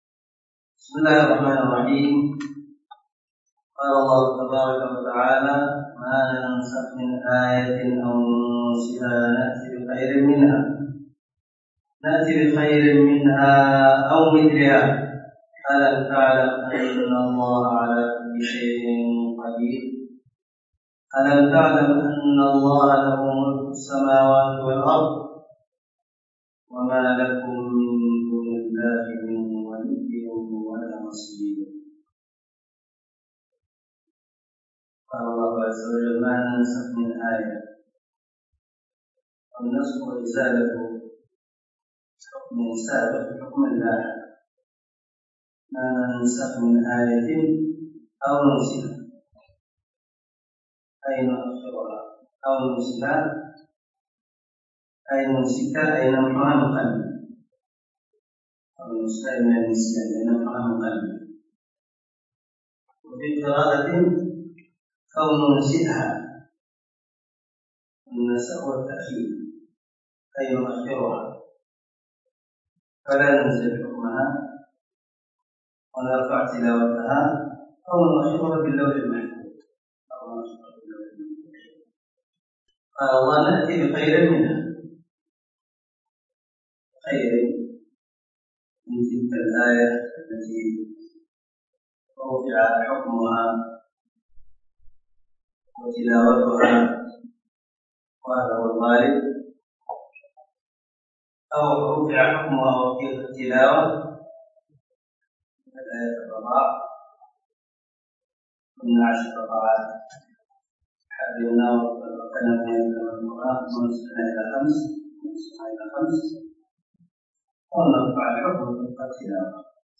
سلسلة_الدروس_العلمية
✒ دار الحديث- المَحاوِلة- الصبيحة.